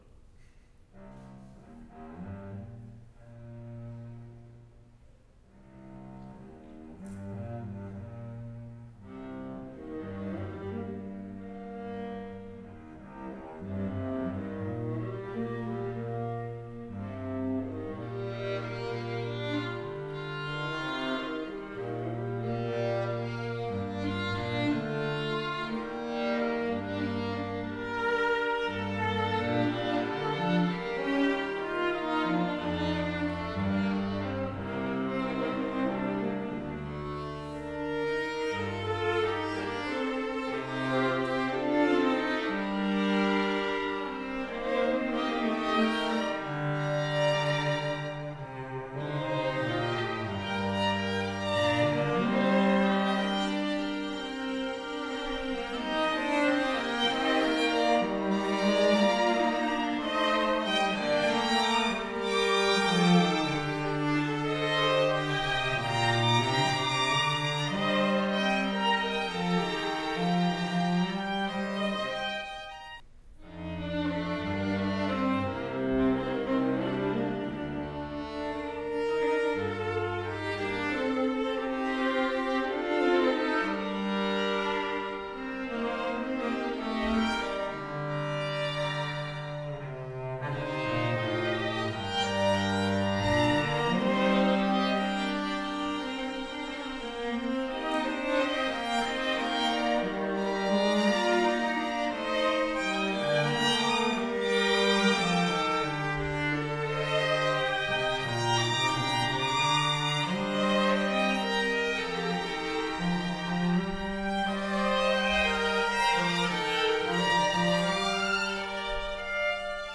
String Quartet, score 2018